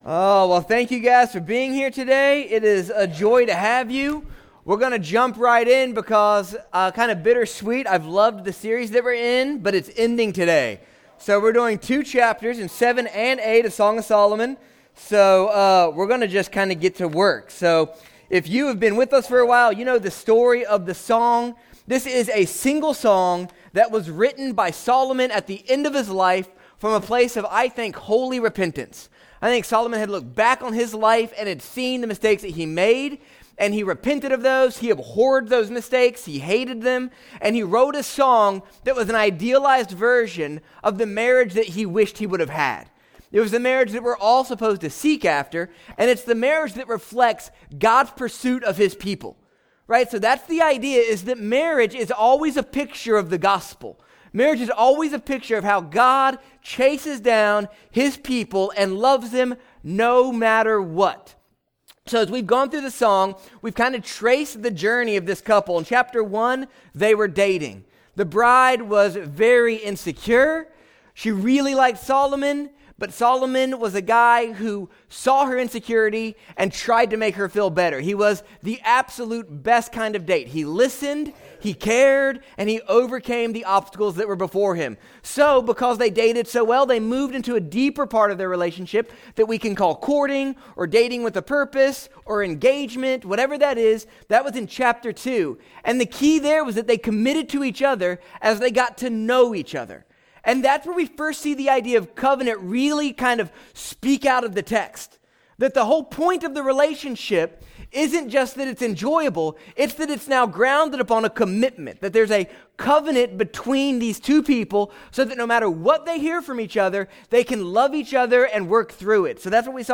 Sermons | South Shore Church